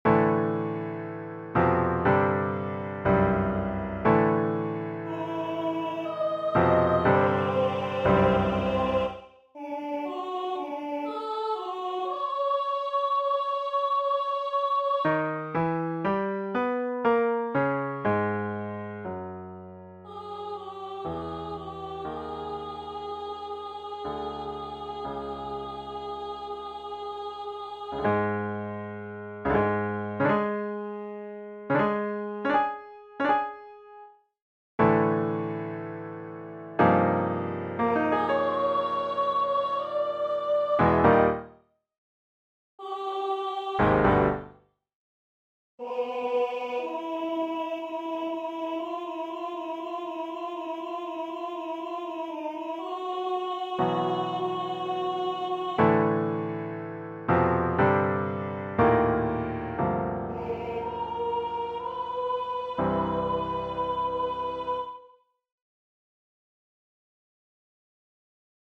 Voice and Piano